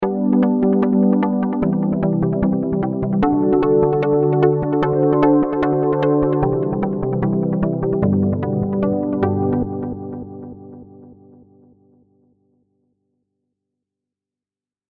2. Simple chord progressions (role)